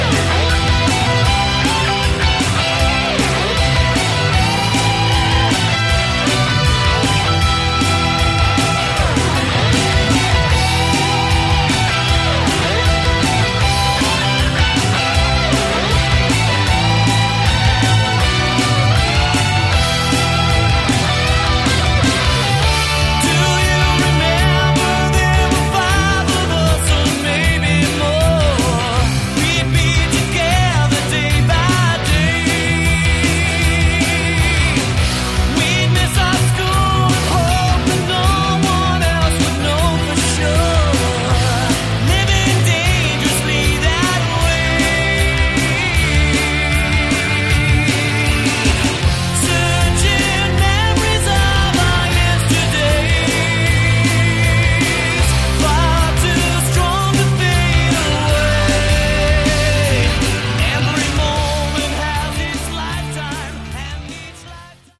Category: Hard Rock
guitars, lead and backing vocals, keyboards
drums, percussion